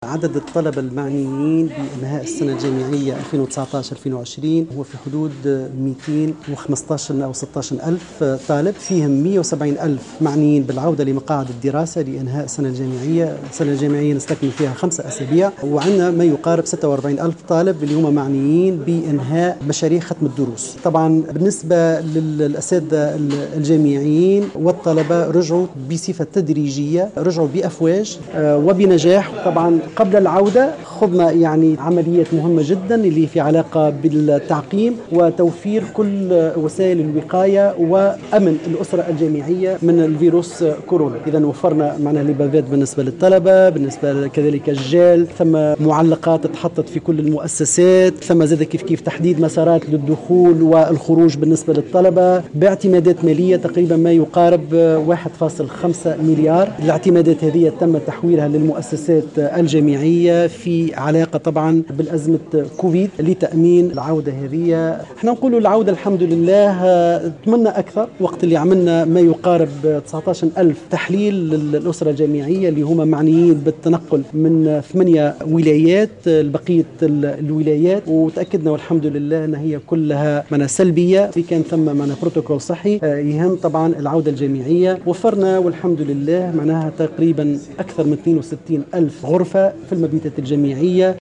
وقال شورى في تصريح للجوهرة أف أم ، على هامش ندوة صحفية خُصّصت لتقديم تفاصيل إستئناف السنة الجامعية ، إنه قد تم رصد إعتمادات إضافية قدرت ب 1,5 مليار لتوفير كل وسائل الوقاية وتأمين المؤسسات الجامعية توقيا من فيروس كورونا ، كما تم إجراء 19 ألف تحليل للأسرة الجامعية من طلبة و أساتذة وكانت جميعها سلبية وفق ذات المصدر .